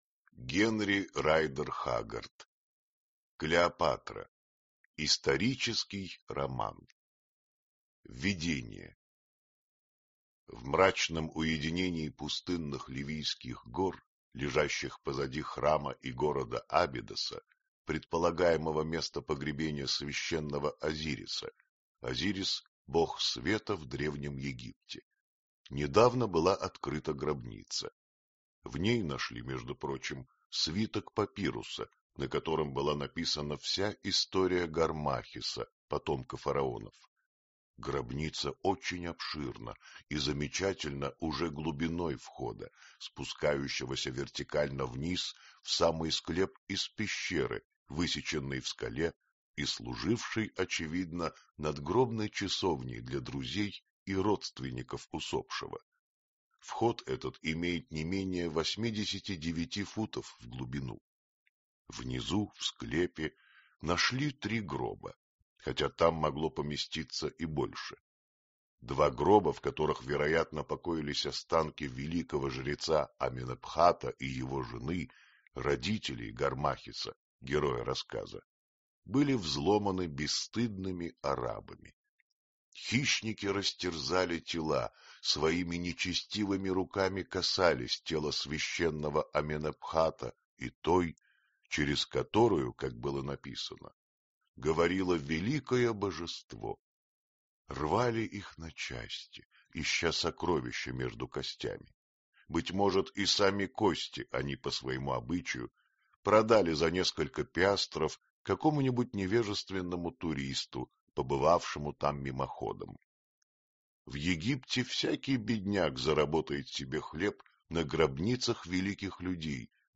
Аудиокнига Клеопатра | Библиотека аудиокниг